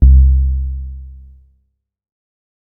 MoogSubDrop 008.WAV